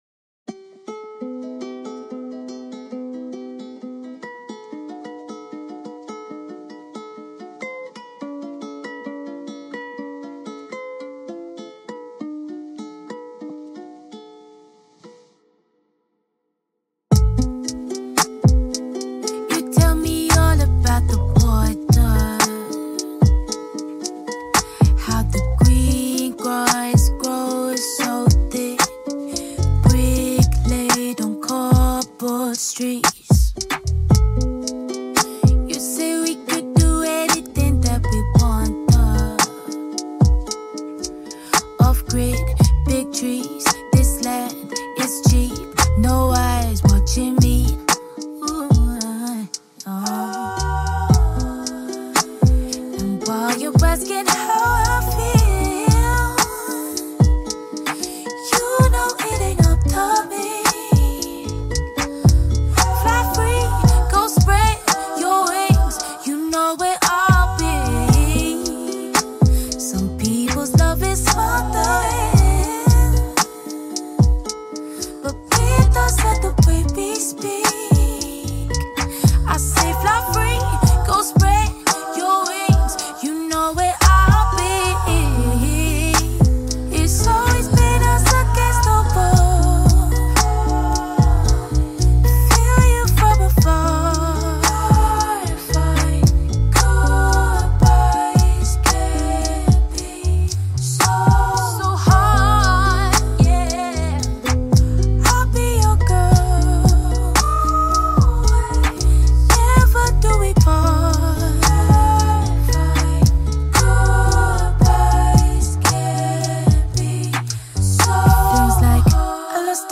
Talented vocalist